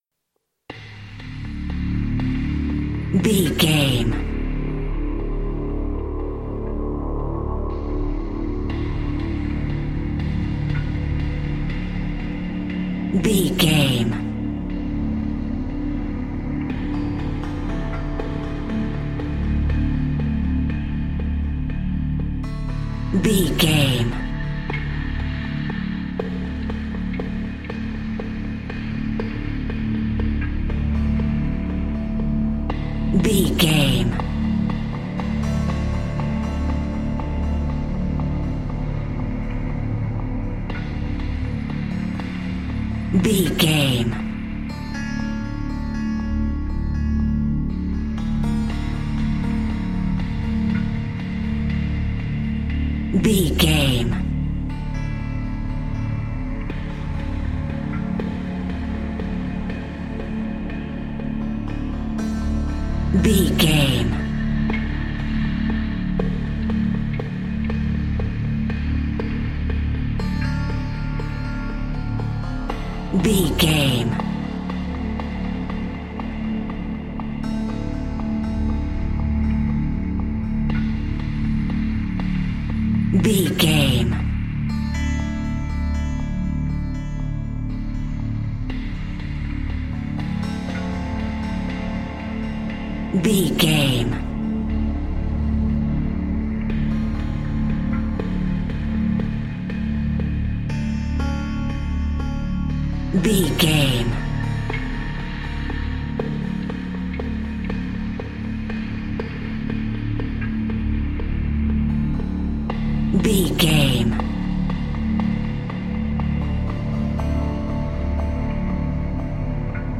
Thriller
Aeolian/Minor
D
harpsichord
synthesiser
percussion
ominous
dark
haunting
creepy